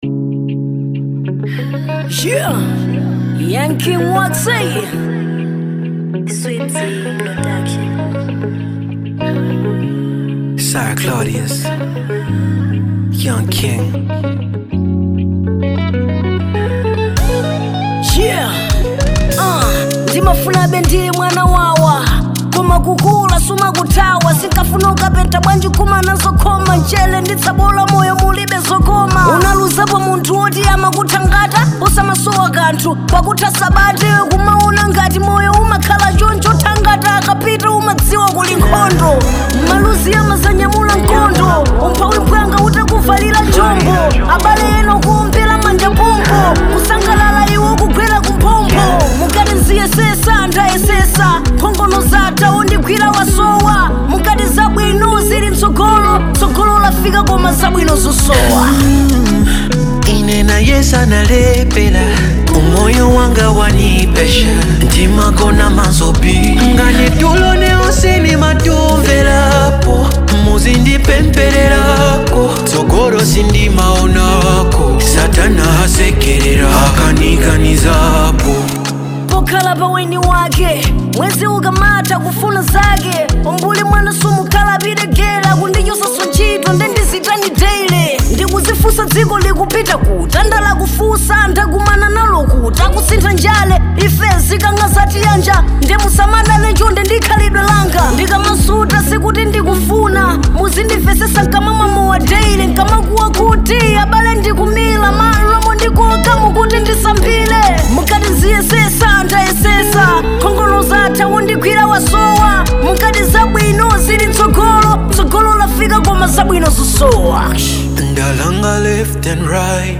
Genre Afrobeat